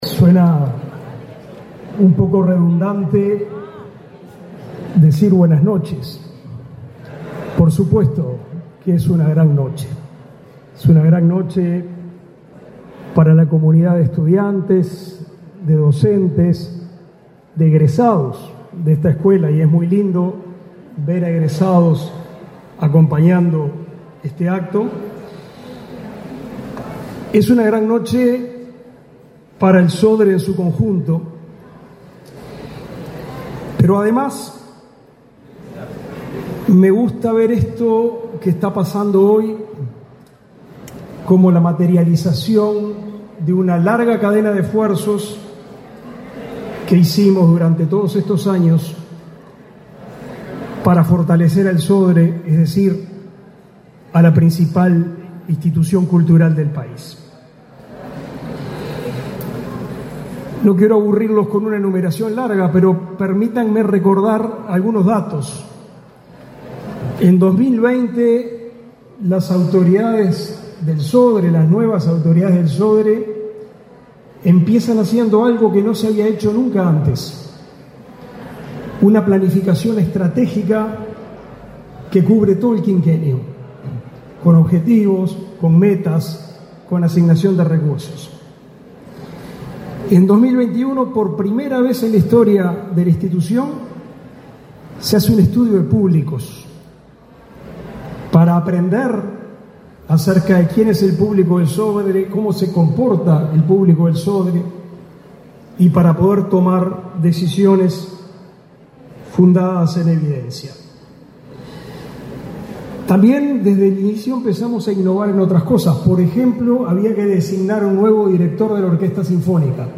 Palabras del ministro de Educación y Cultura, Pablo da Silveira
Palabras del ministro de Educación y Cultura, Pablo da Silveira 25/02/2025 Compartir Facebook X Copiar enlace WhatsApp LinkedIn Este 24 de febrero, se realizó la inauguración del edificio de las Escuelas del Sodre, con la presencia de la vicepresidenta de la Republica, Beatríz Argimón. En el evento disertó el ministro de Educacion y Cultura, Pablo da Silveira.